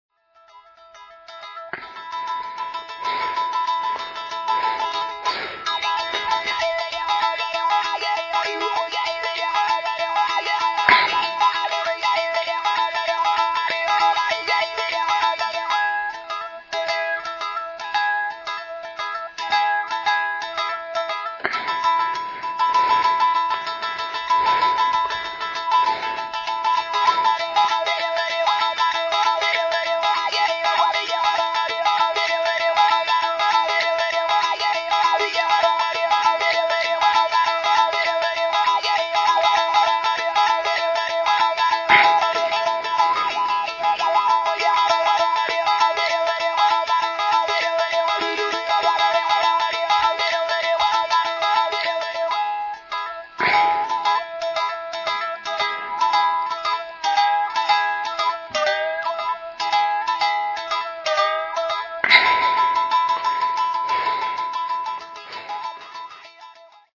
Musique des hautes-terres Huli
Alternant percussions, chants, sifflets et guimbardes rudimentaires, tous les sons sont ici pris in situ (selon la ligne éditoriale de la collection Prophet) et laissent entendre les souffles, cris et échos propres à ces environnements montagneux. Une musique aux résonances africaines et aux silences, glissandis et micro-tonalités entièrement asiatiques.